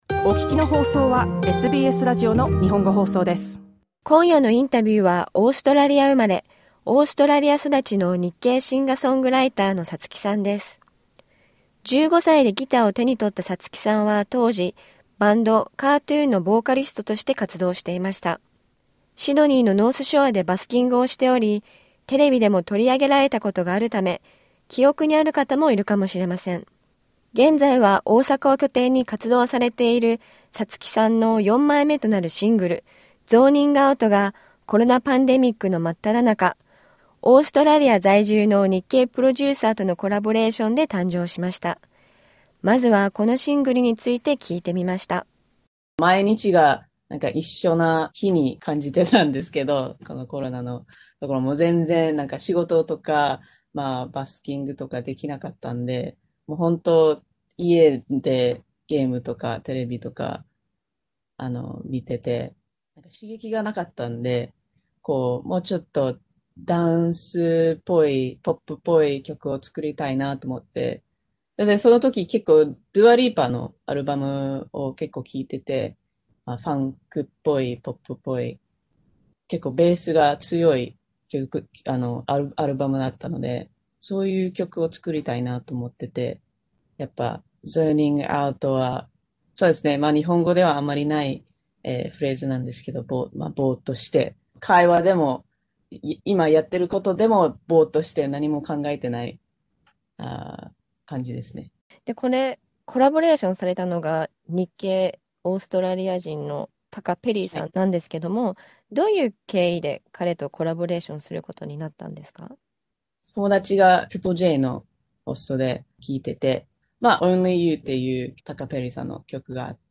インタビューではこの他にも、音楽活動を行う上で感じた日本とオーストラリアの違いなど、色々と語っていただきました。